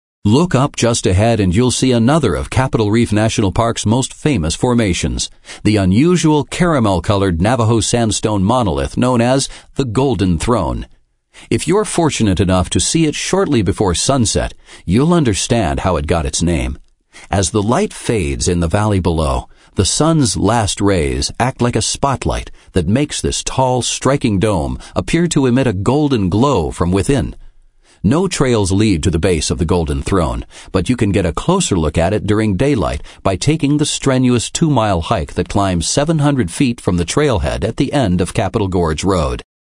Capitol Reef National Park is one of the country’s great driving experiences, ideal for a Just Ahead smartphone audio tour.
Sample a few of the hundreds of stories that play automatically as you drive to and through Capitol Reef: